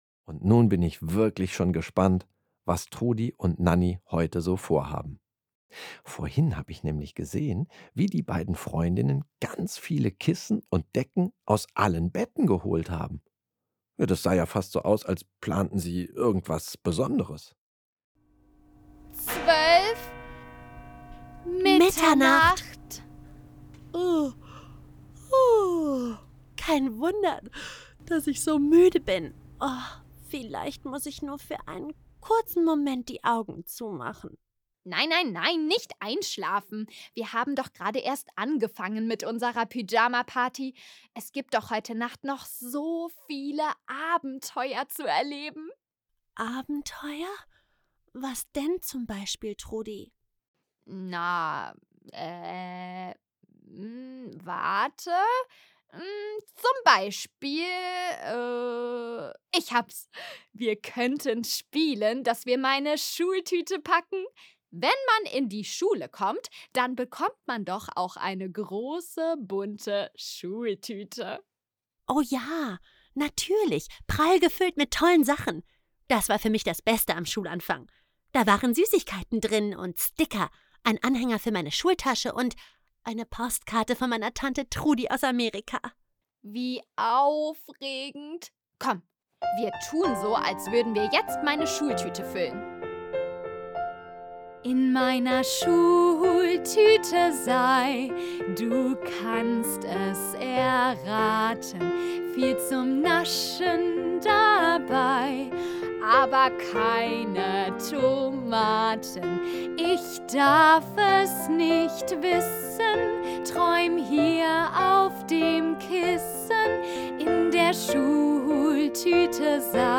Mit der Gute-Nacht-Geschichte „Trudis Pyjama Party“ als Hörspiel mit Musik finden die Kleinen vom aufregenden Tag sanft in den Schlaf.